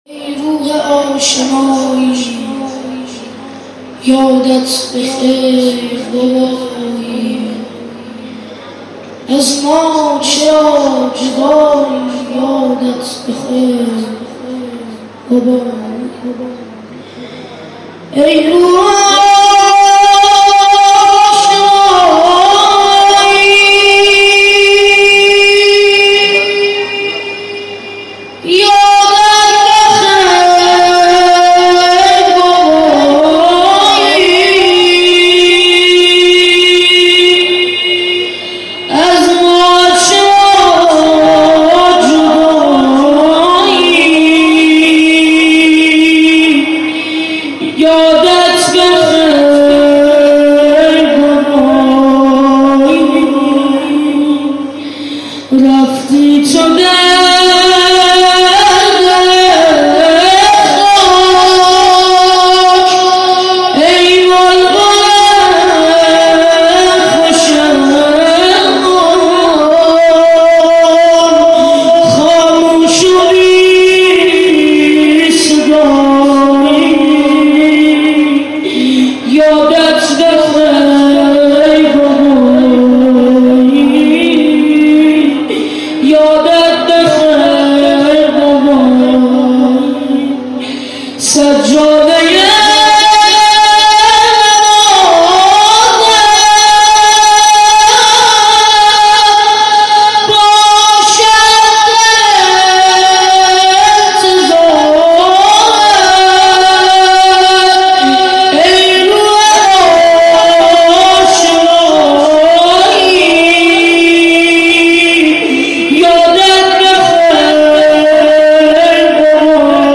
روضه خوانی